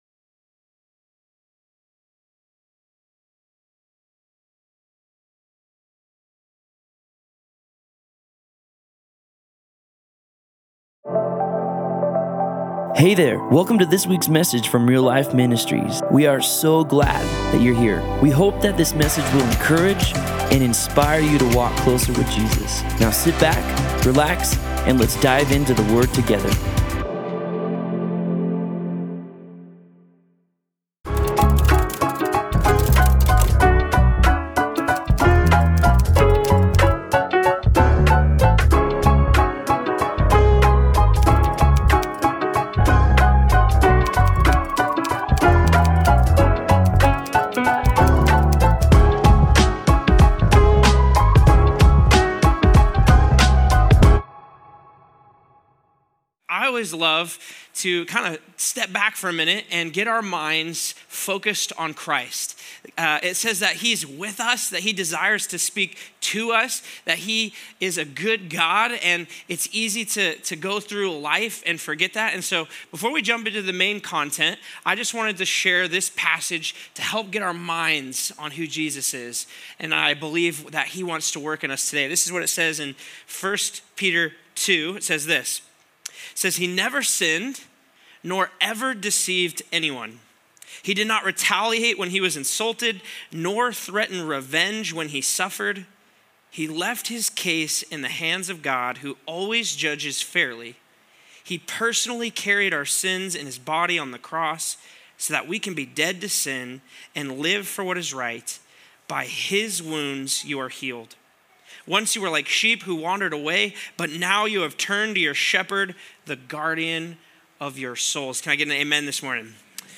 Other Sermon